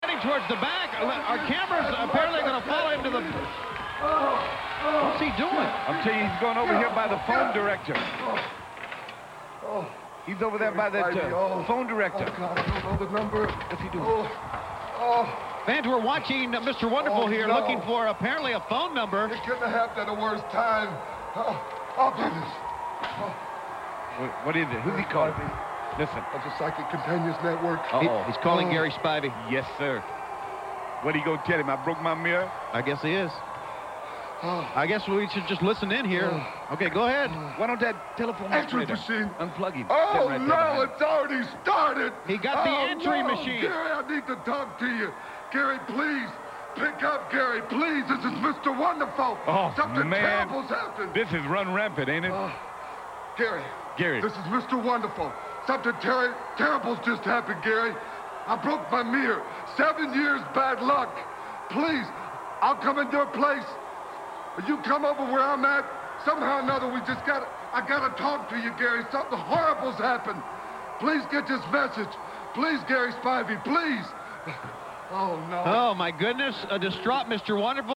he begged and pleaded for Gary to pick up the phone and call him back! And those jerk announcers Tony Schiavone and Dusty Rhodes weren’t giving him any support at all on commentary!